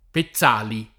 [ pe ZZ# li ]